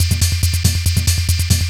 DS 140-BPM A7.wav